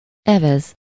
Ääntäminen
IPA : /ɹoʊ/